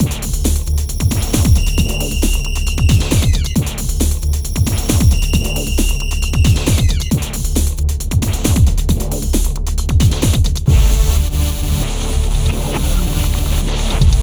31 Futurefunk-d.wav